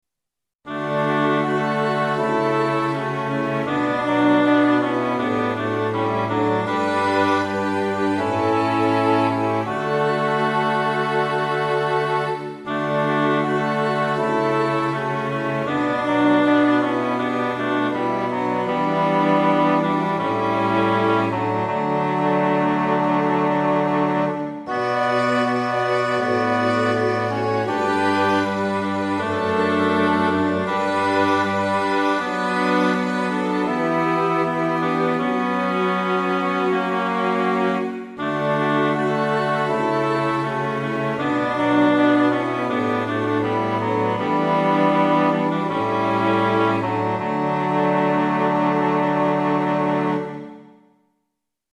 ♪聖歌隊練習補助
Tonality = D
Pitch = 440
♪   フルート オーボエ E.ホルン ファゴット